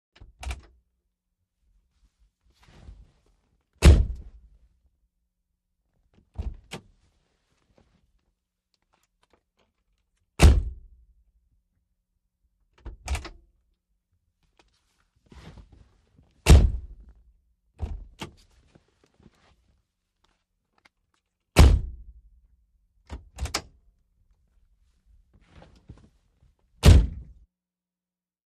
VEHICLES ASTON MARTIN:INT: Getting in & out, various.